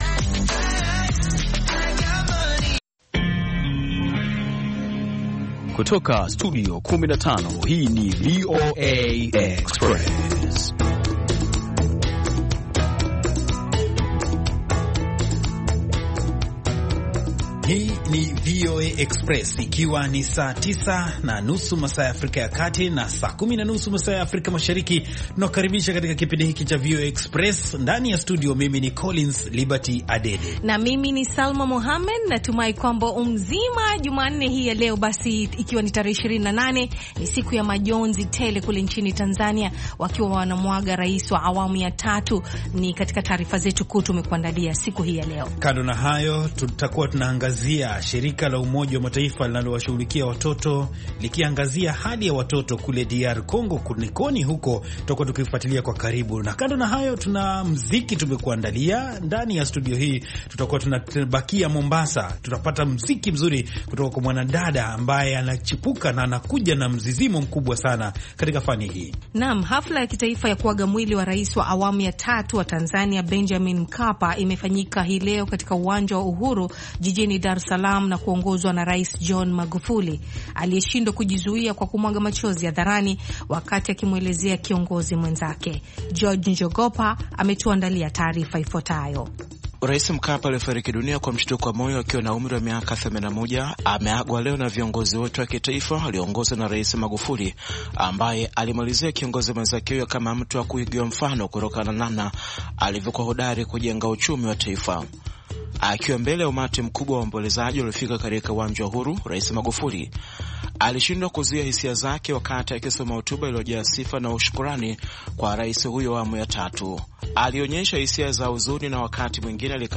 VOA Express ni matangazo mapya yenye mwendo wa kasi yakiangalia habari mpya za mchana na maelezo ya maswala yanayohusu vijana na wanawake. Matangazo haya yanafuatilia habari zilizojitokeza nyakati za mchana na ripoti za kina za habari ambazo hazisikiki sana katika matangazo mengineyo. VOA Express pia inafuatilia kwa karibu sana maswala yanayovuma katika mitandao ya kijamii kama sehemu moja muhimu ya upashanaji habari siku hizi.